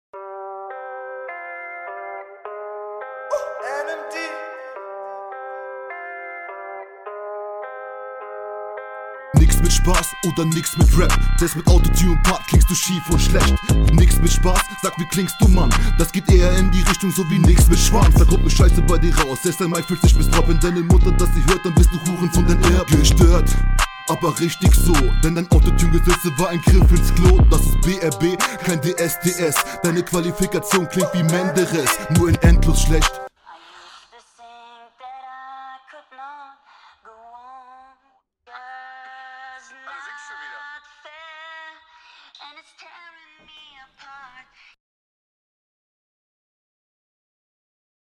nix mit schwanz aua dsds menderez aua flow & stimmeinsatz ganz gut